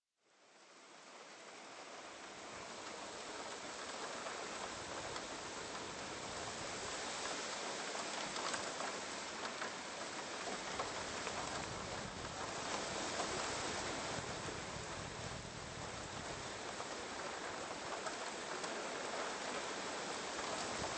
90 minuti di suoni naturali professionalmente registrati.
Vento e Pioggia (4 files. Durata: 40 minuti)
La pioggia è uno dei suoni più rilassanti offerti dalla natura.
Poi una folata di vento le cui melodie offrono una musica vibrante in lontananza.
rain1sample.mp3